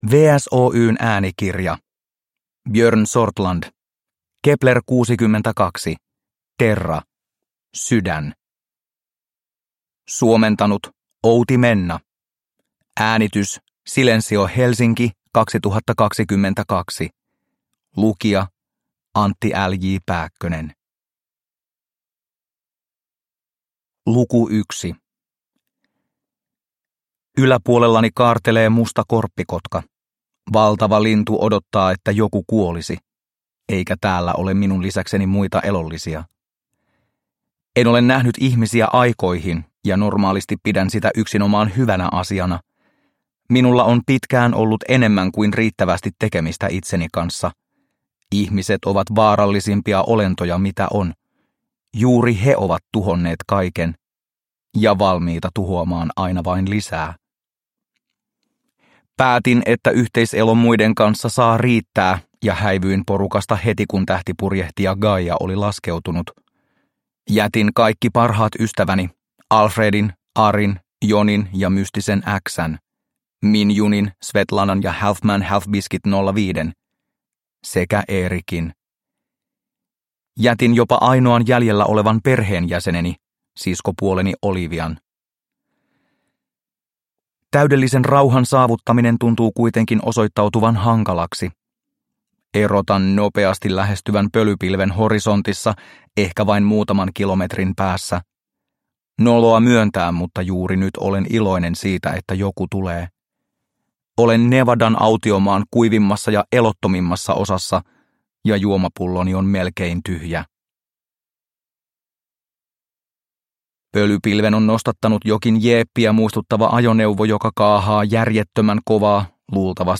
Kepler62 Terra: Sydän – Ljudbok – Laddas ner